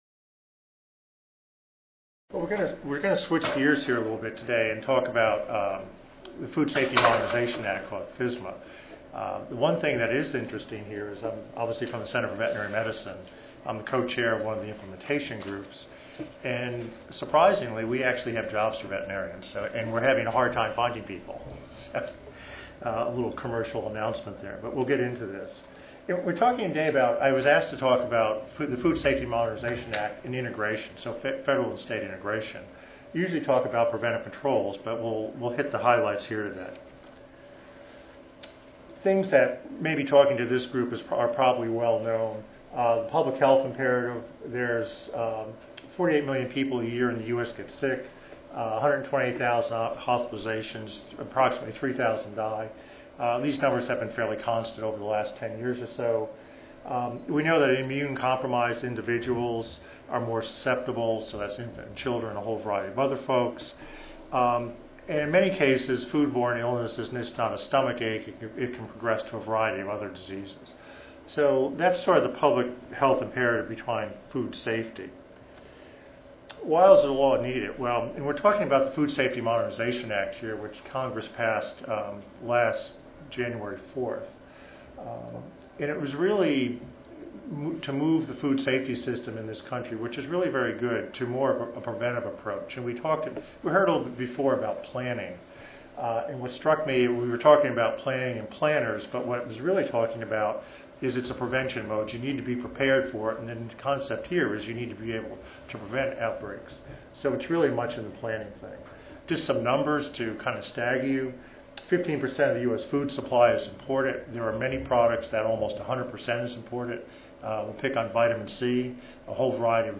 Oral Invited speakers will Describe national programs, issues and legislation affecting Veterinary Public Health